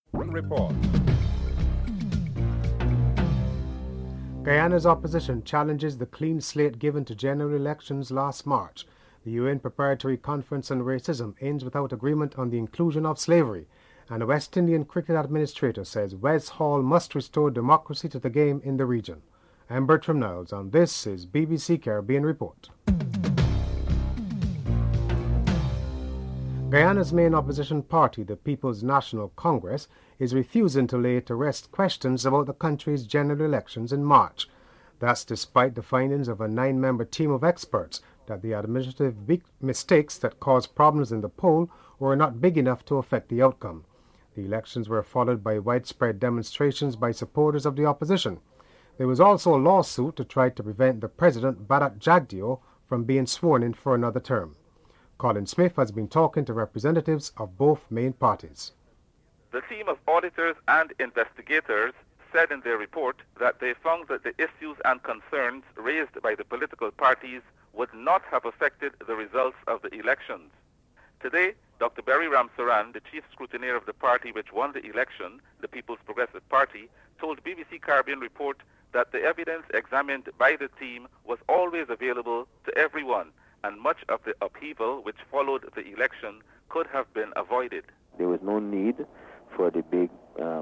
1. Headlines (00:00-00:25)